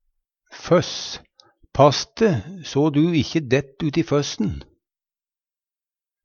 føss - Numedalsmål (en-US)